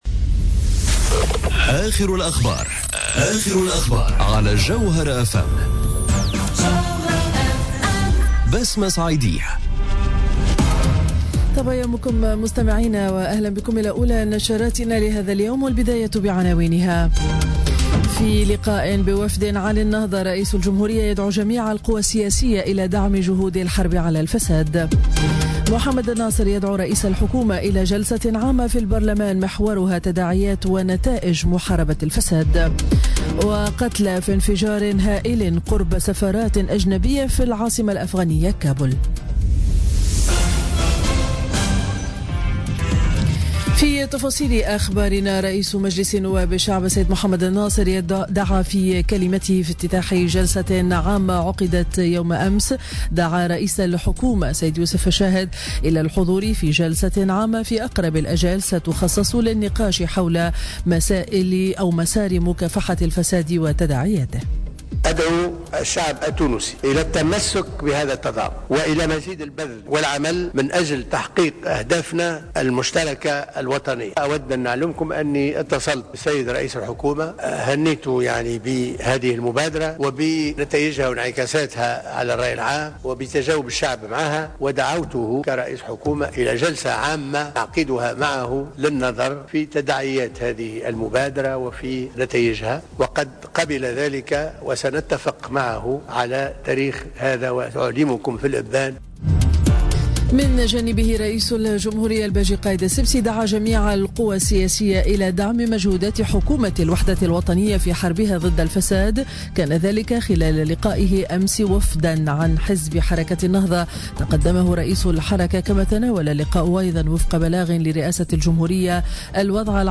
نشرة أخبار السابعة صباحا ليوم الإربعاء 31 ماي 2017